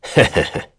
Lusikiel-Vox-Laugh.wav